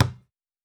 Foley Sports / Basketball / Generic Bounce Normal.wav
Generic Bounce Normal.wav